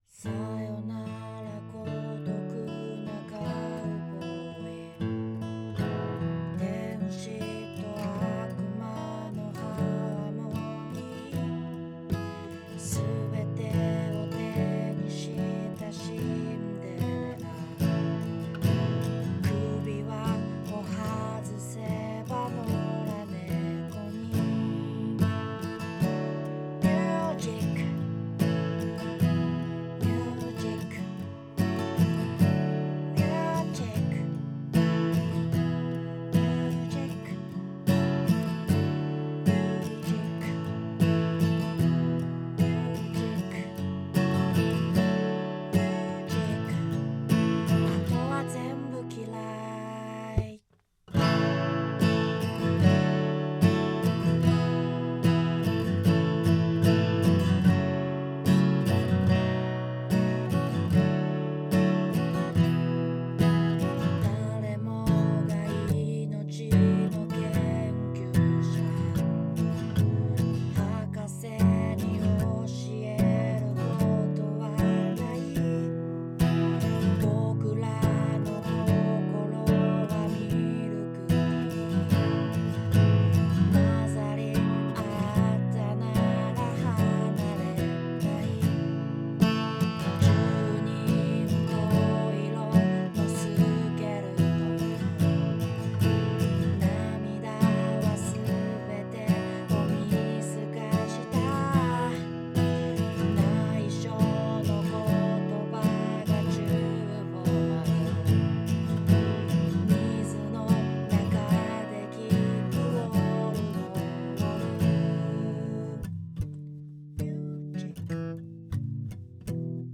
アコースティックギターは88RLBです。
Neveらしさがよく分かる音源になったと思います。
Music_AG_with_C414-88RLB.wav